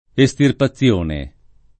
[ e S tirpa ZZL1 ne ]